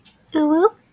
Girl Uwu